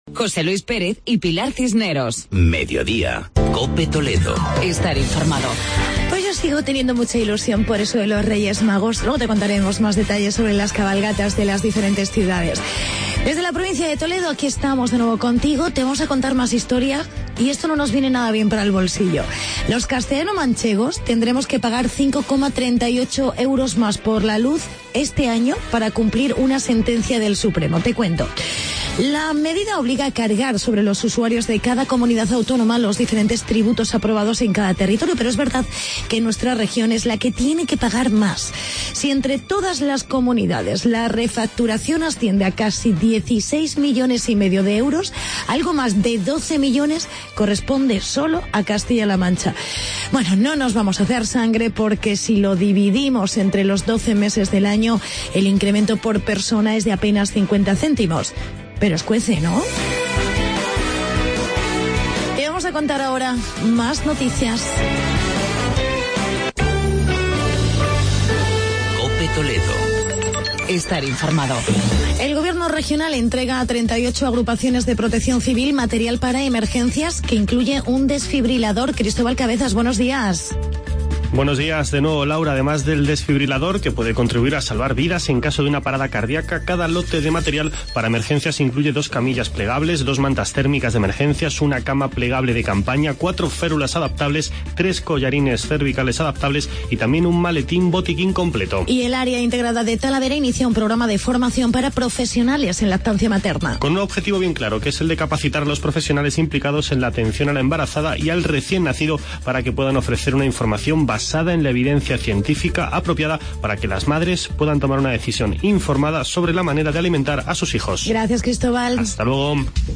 Actualidad, agenda cultural y entrevista